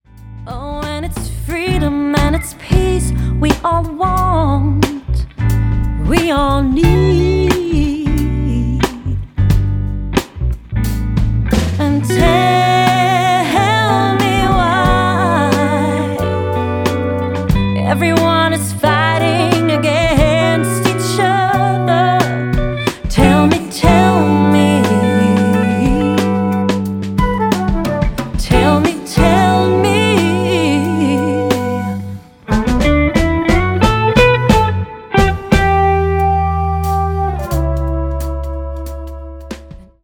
music duo
Singer / Songwriter
Guitarist